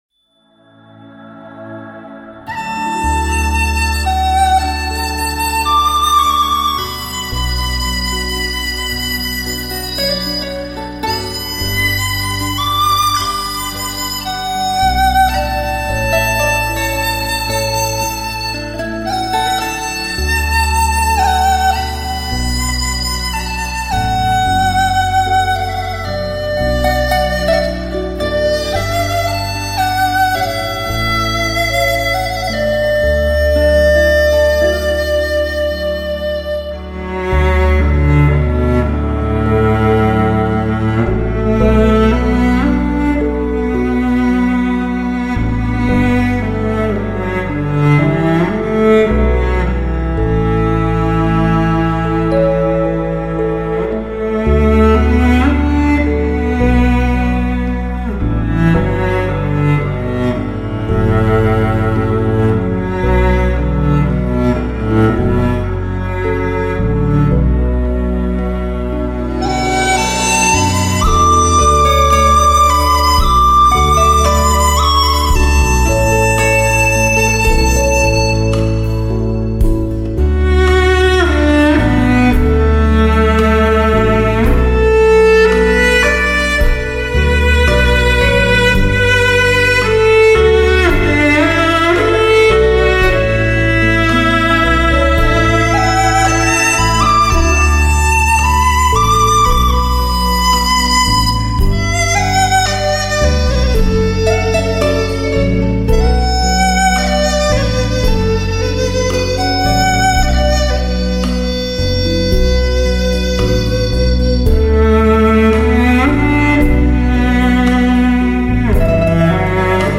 如歌、如诉感人抒情的大提琴
大提琴
大提琴它的音乐时而华丽
时而朦胧 时而低沉 时而富有歌唱性
具有人生般的感染力 音乐抒情 娓娓道来